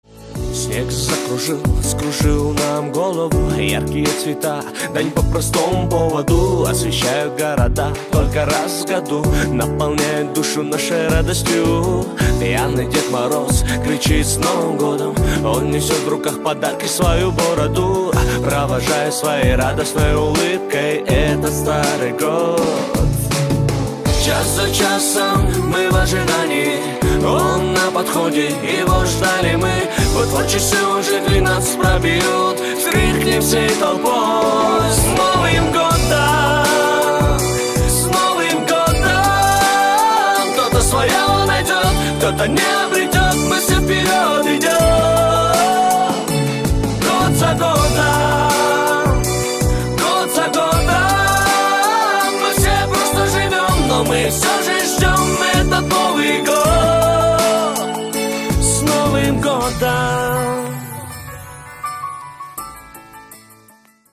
• Качество: 256, Stereo
поп
мужской вокал
громкие
Хип-хоп
медленные